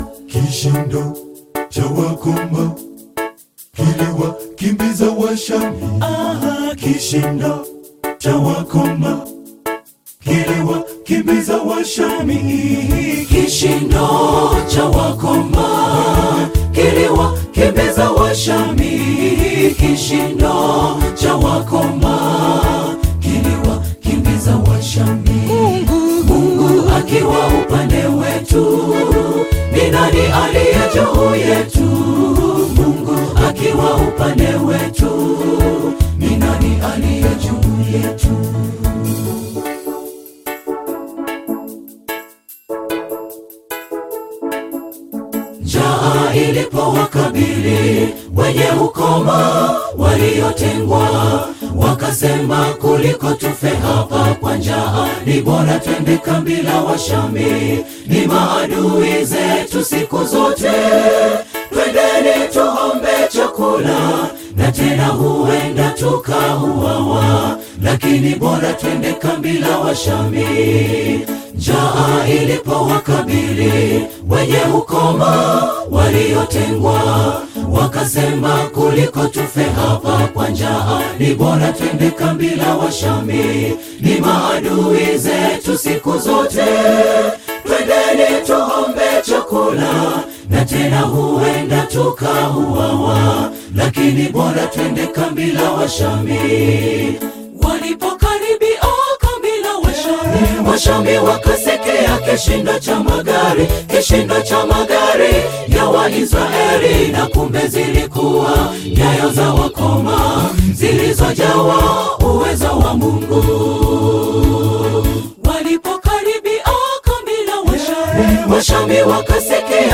Tanzanian gospel choir from Arusha
gospel song